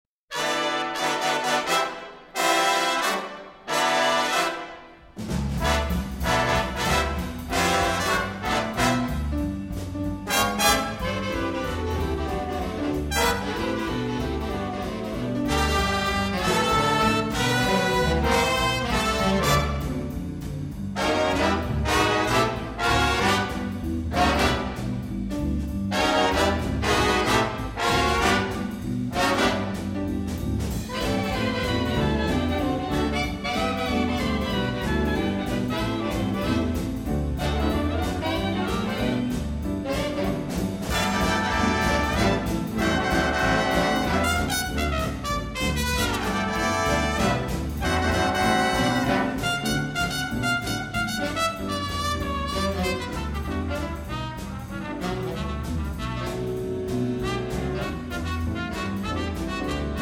(Jazz Version)